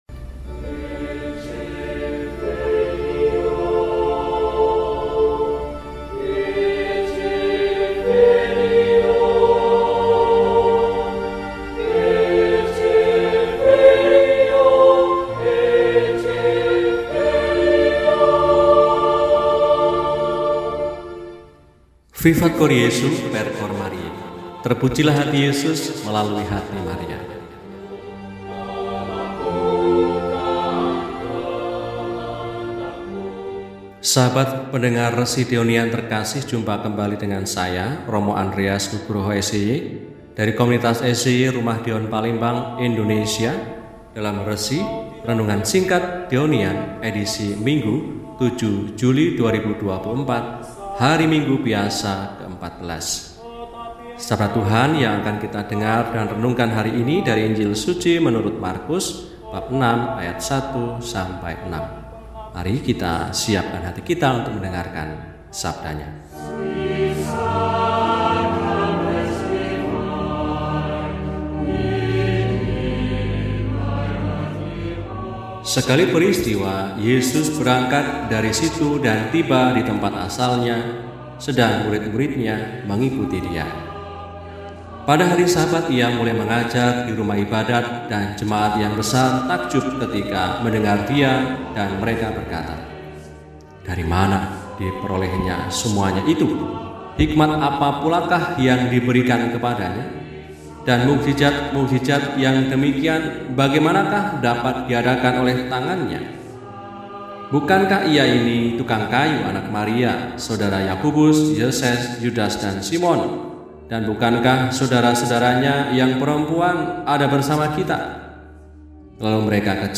Minggu, 07 Juli 2024 – Hari Minggu Biasa XIV – RESI (Renungan Singkat) DEHONIAN